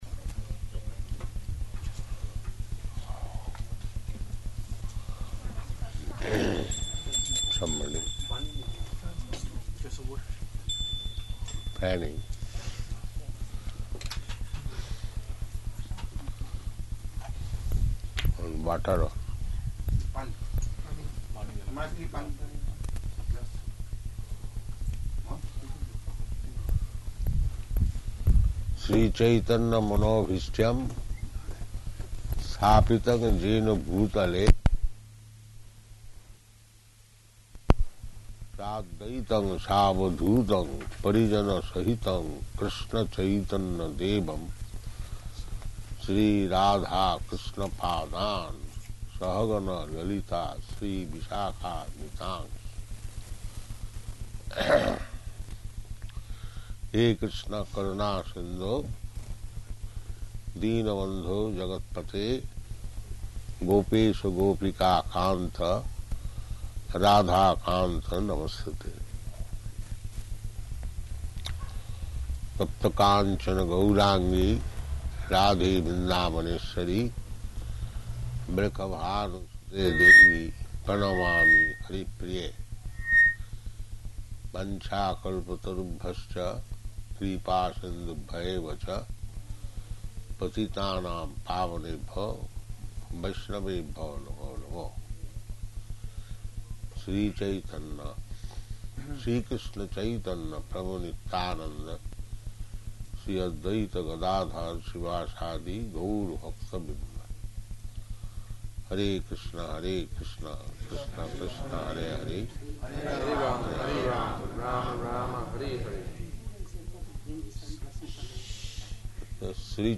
Sunday Feast Lecture
Sunday Feast Lecture --:-- --:-- Type: Lectures and Addresses Dated: July 25th 1976 Location: London Audio file: 760725L2.LON.mp3 Prabhupāda: Somebody fanning, and water also.